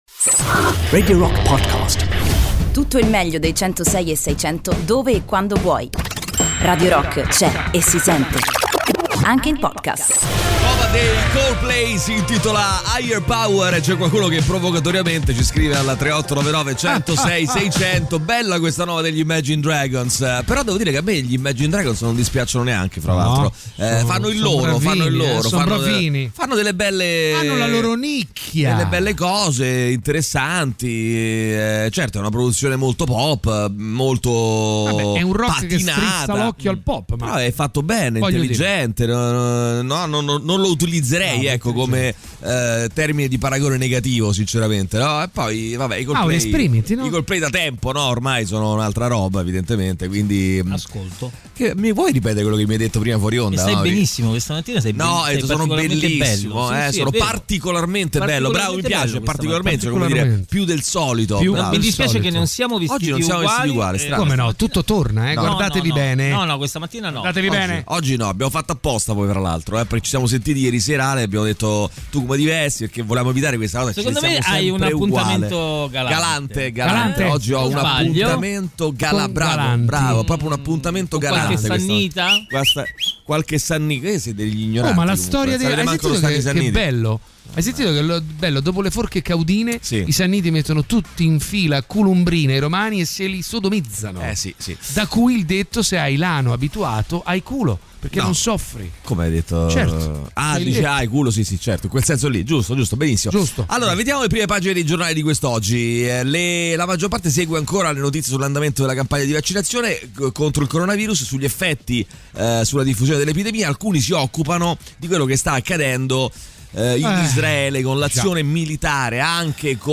in diretta dalle 08.00 alle 10.00 dal Lunedì al Venerdì sui 106.6 di Radio Rock.